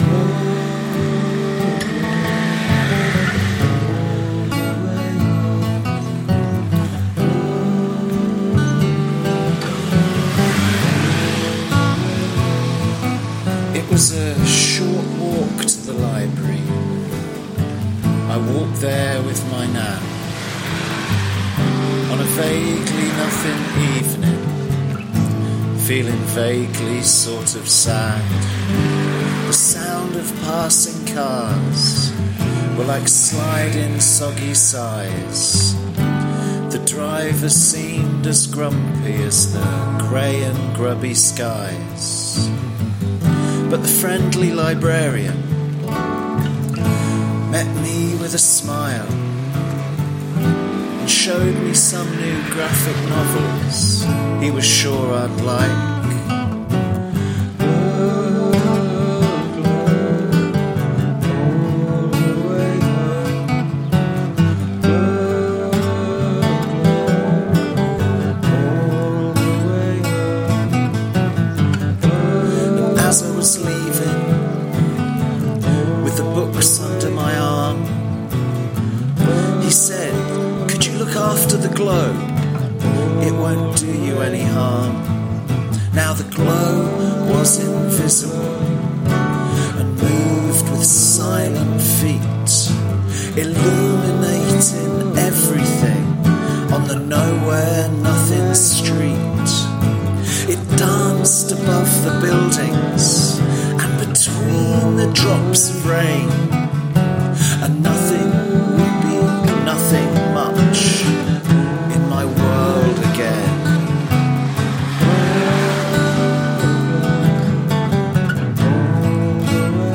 …the audio recording is very influenced by my favourite band of the last few years, The Clientele!